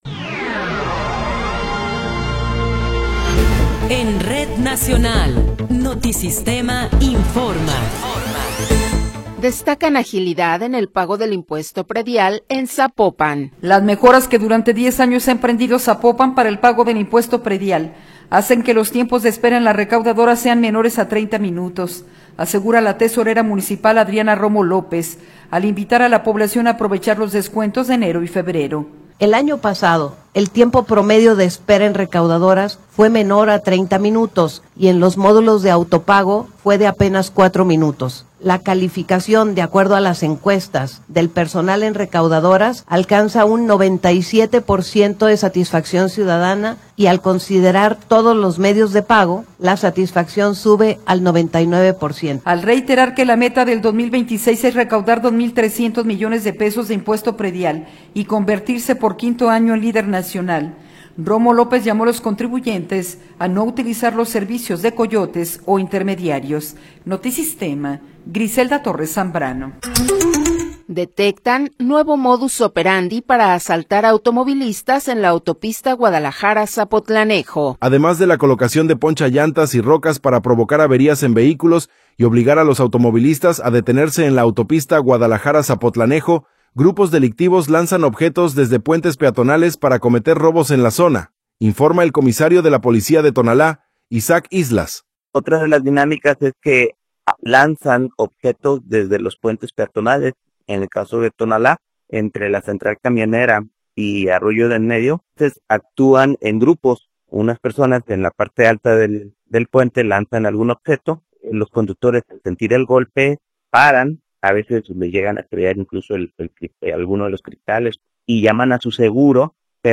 Resumen informativo Notisistema, la mejor y más completa información cada hora en la hora.